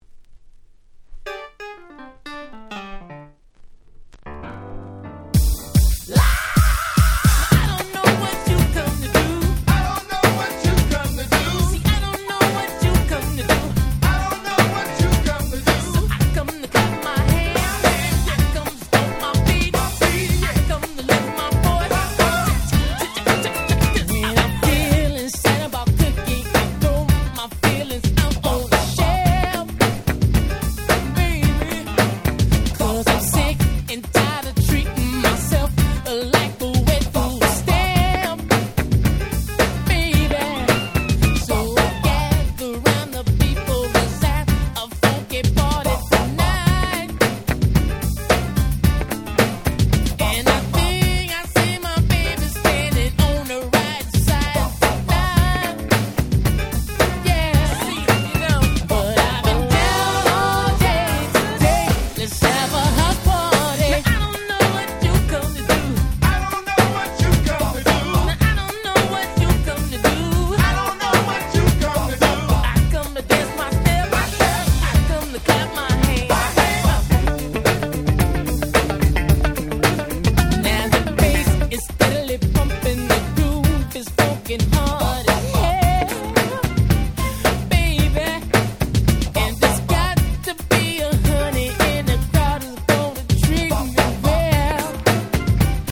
コーナーストーン 90's キャッチー系 NJS ハネ系 New Jack Swing ニュージャックスウィング